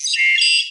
computer5.wav